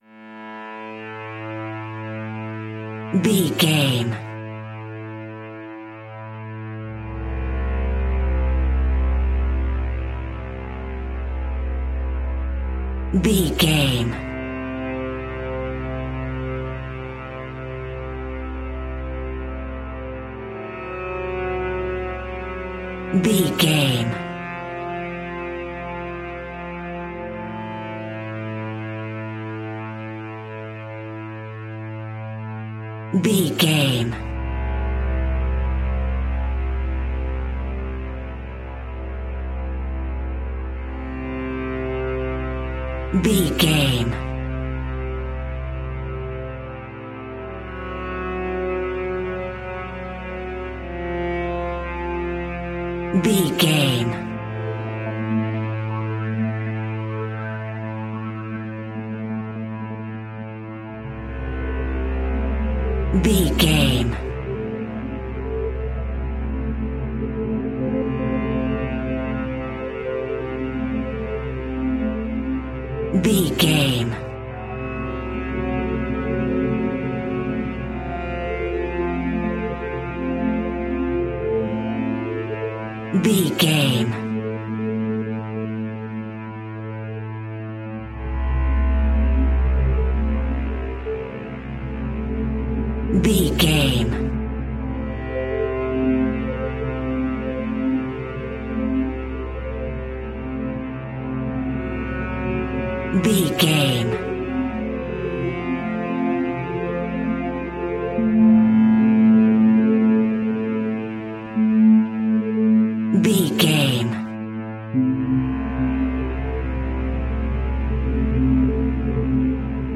Aeolian/Minor
Slow
ominous
eerie
industrial
ethereal
cello
double bass
strings
synthesiser
instrumentals
horror music